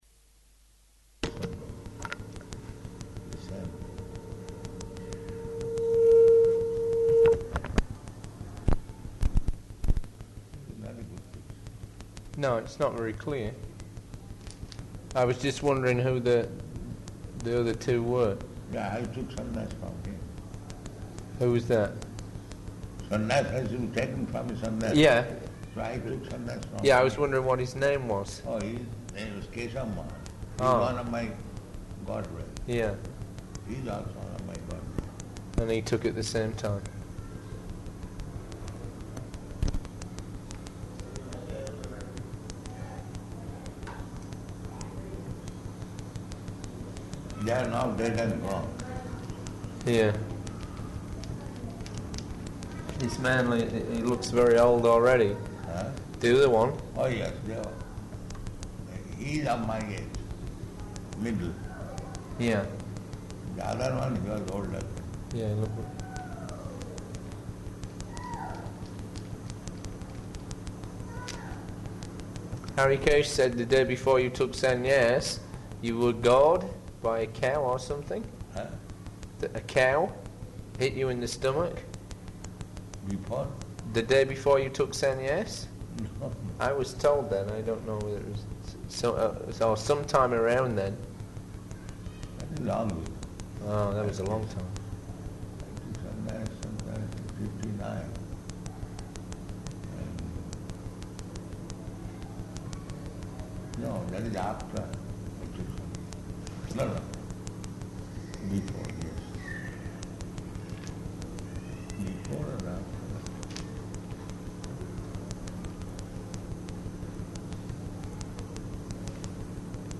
Room Conversation
Room Conversation --:-- --:-- Type: Conversation Dated: October 3rd 1976 Location: Vṛndāvana Audio file: 761003R1.VRN.mp3 Prabhupāda: ...position. It is not a good position.